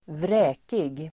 Uttal: [²vr'ä:kig]